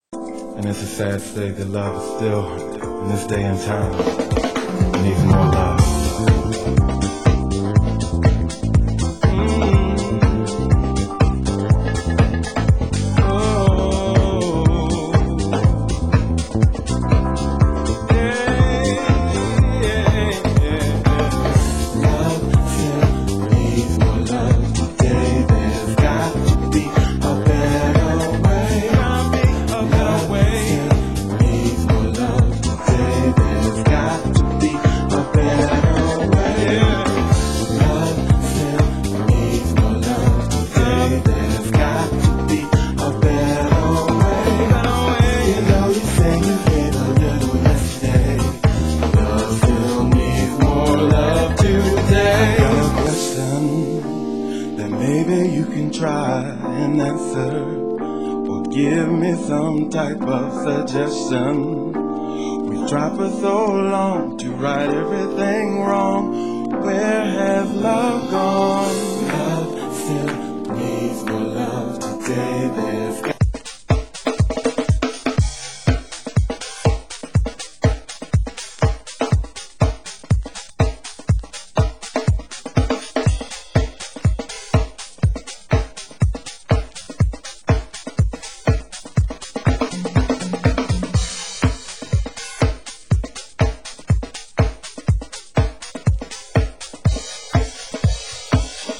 Genre: French House